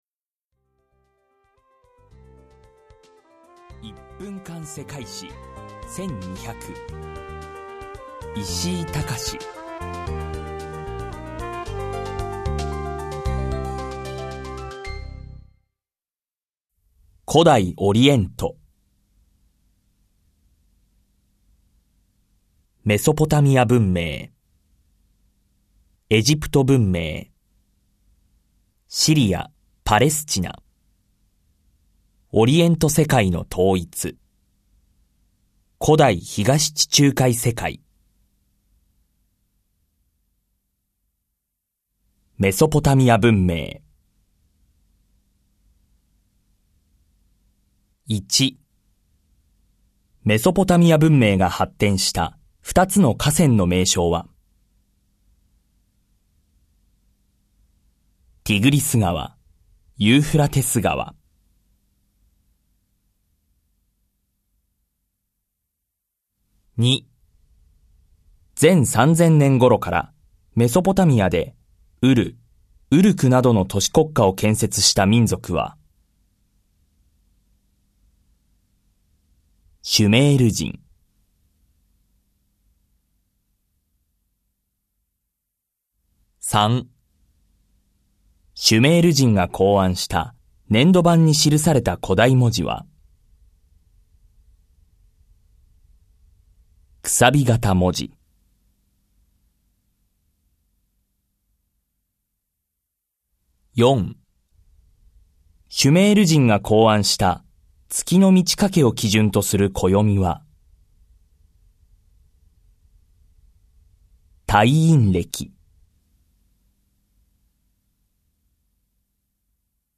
[オーディオブック] 1分間世界史1200